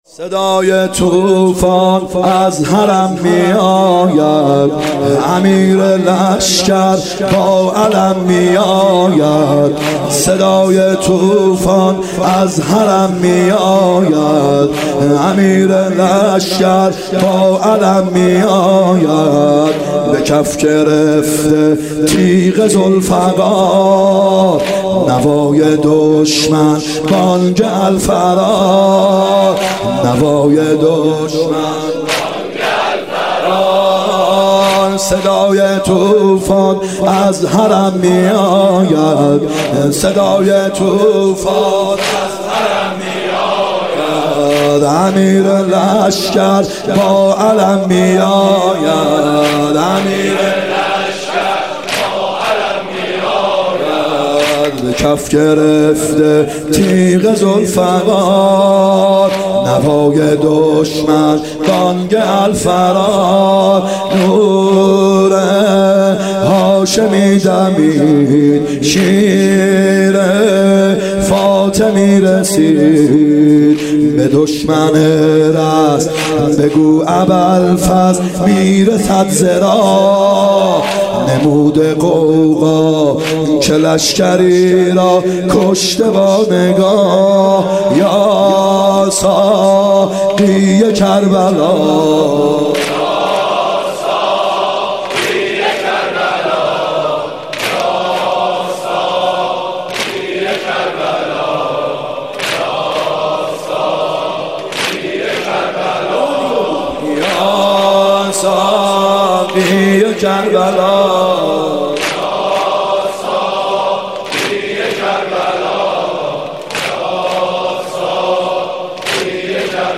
محرم 94(هیات یا مهدی عج)